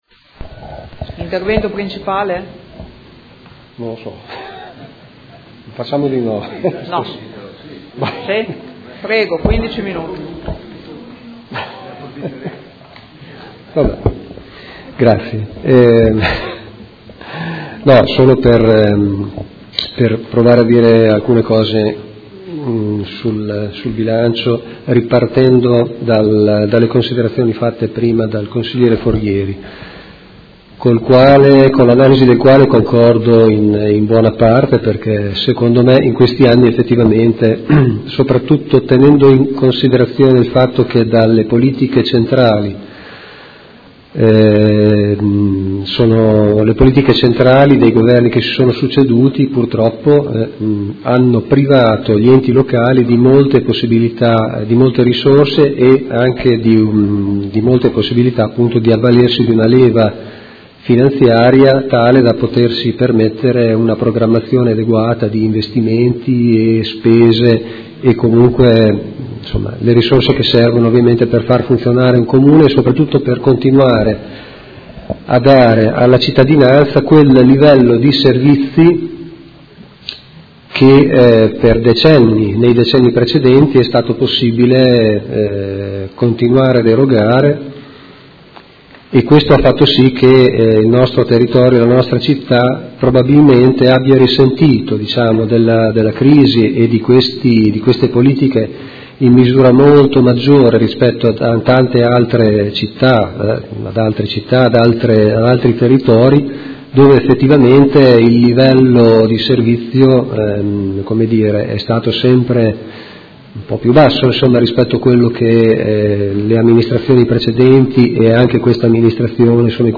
Seduta del 20/12/2018. Dibattito su delibera di bilancio, Ordini del Giorno, Mozioni ed emendamenti